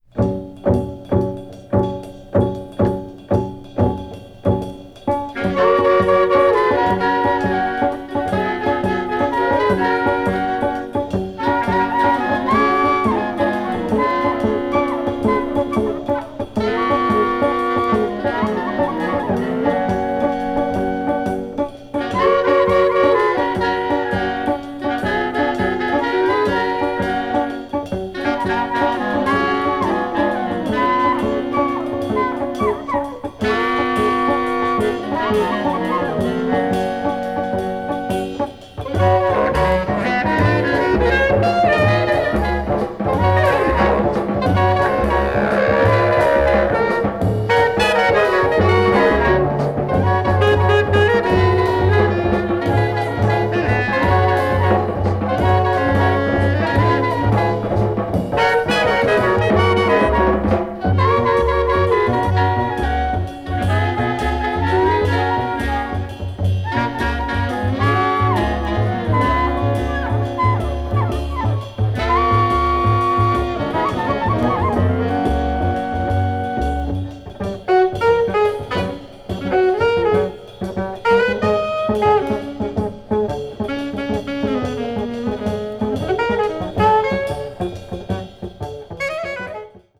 bass clarinet
trumpet
vibraphone
drums), showcasing a gently grooving waltz jazz on A1.
avant-garde jazz